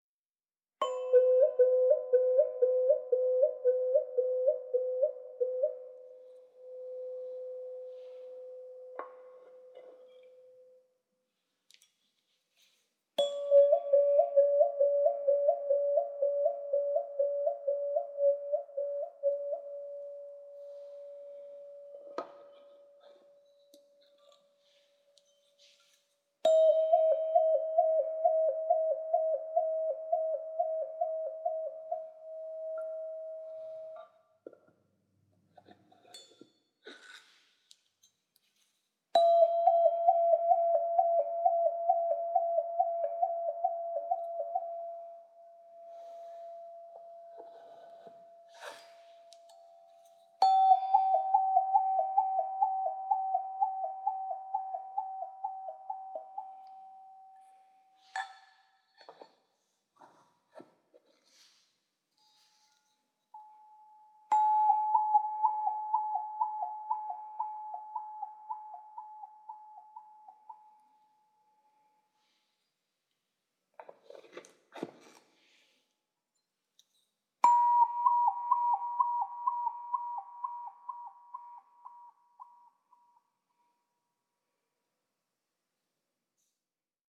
Tuned to 432 Hz, the tubes resonate with a harmonious and soothing tone, perfect for sound healing, meditation, or musical exploration.
Strike the tube, then use your thumb to alternately cover and uncover the Wah-Wah hole, creating the signature, captivating sound that these tubes are known for. This simple yet unique playing technique produces a dynamic and expressive auditory experience.